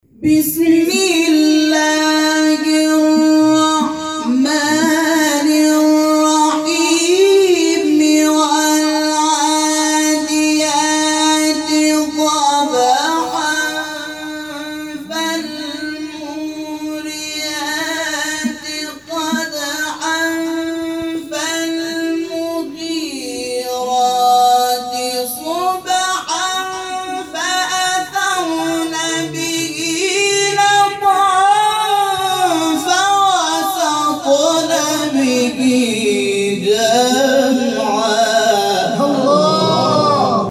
گروه شبکه اجتماعی: نغمات صوتی از تلاوت‌های قاریان به‌نام کشور را می‌شنوید.
سوره عادیات در مقام سگاه